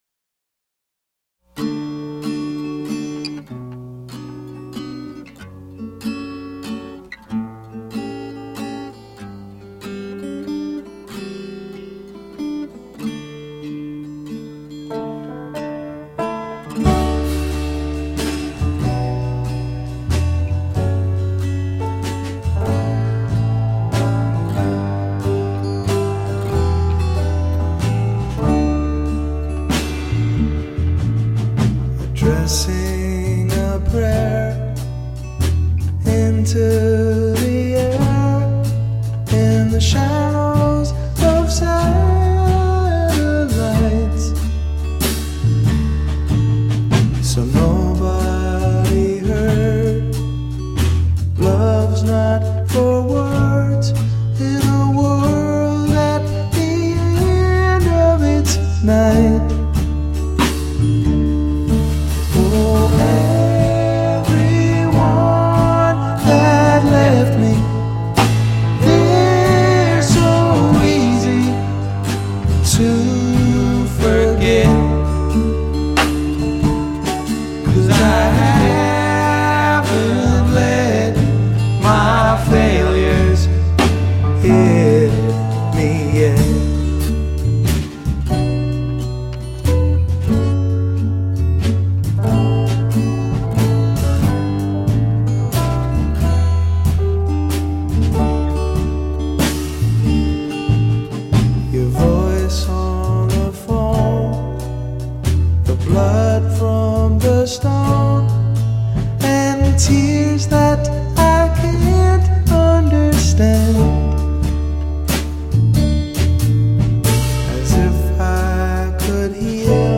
With a acoustic guitar hook
in beautiful organic simplicity, and with glorious harmonies